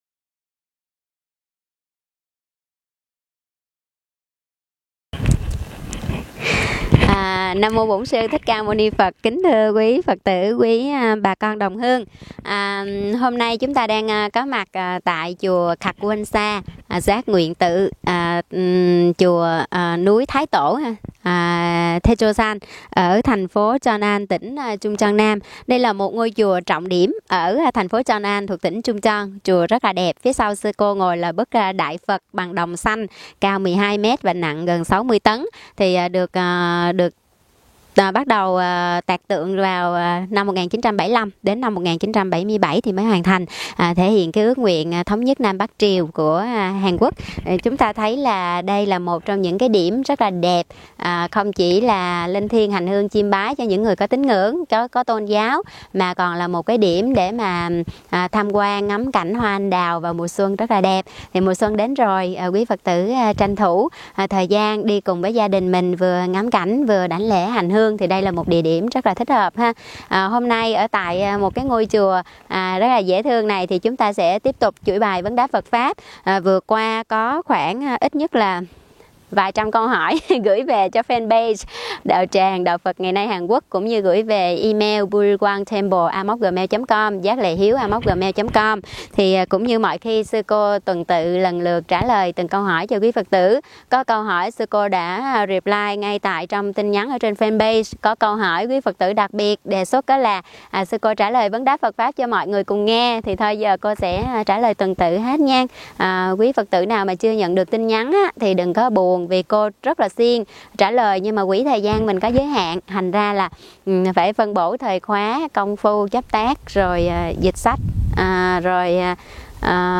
Vấn đáp: Hiến mô tạng, ký ức tế bào và ảnh hưởng tính cách từ người cho